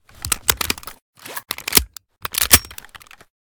vz61_reload_empty.ogg